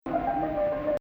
Maqam Rast
Nahawand 8
Rast Fein Ya Gameel 21 Nahawand Octa.mp3